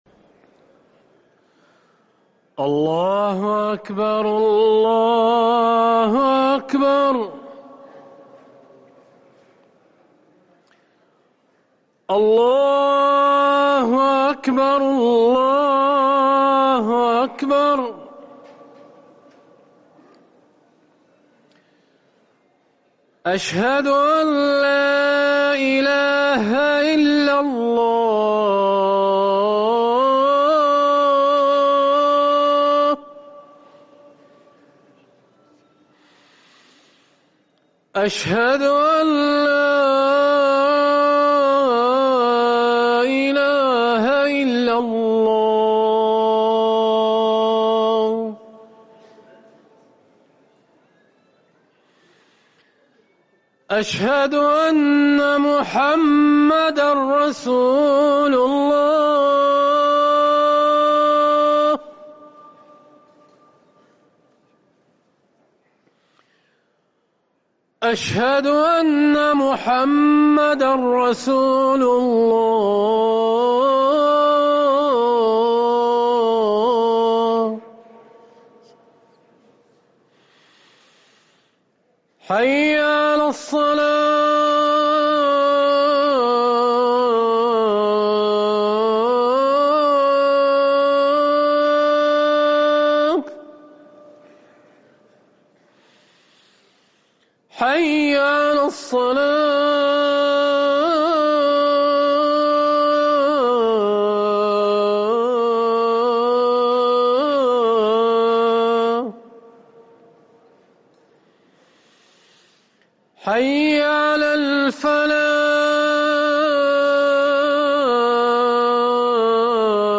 اذان العشاء
ركن الأذان